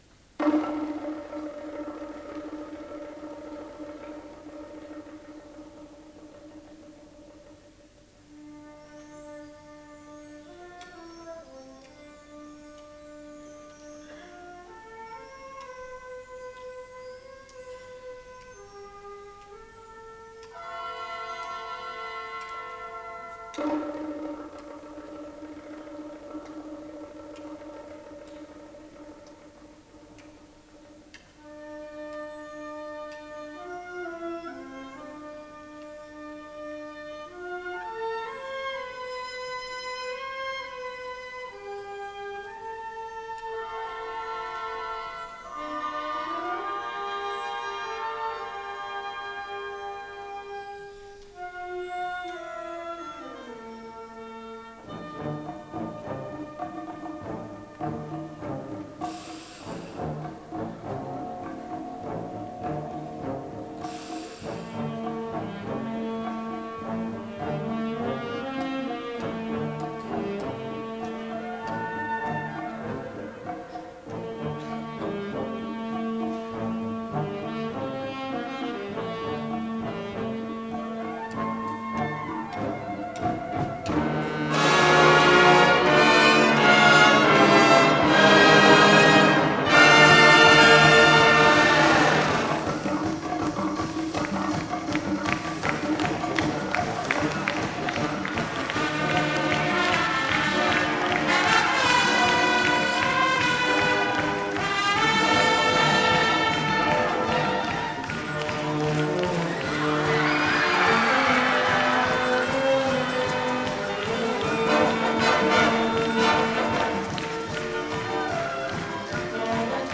第24回 定期演奏会